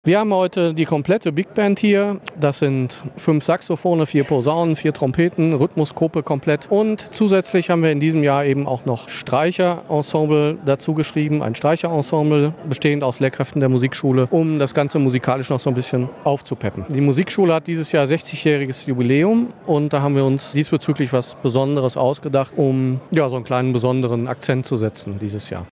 Die Big Band der Max-Reger-Musikschule spielte am 20. Juni ab 18:30 Uhr im Innenhof des Geländes der Elbershallen ihren "Swing in die Ferien".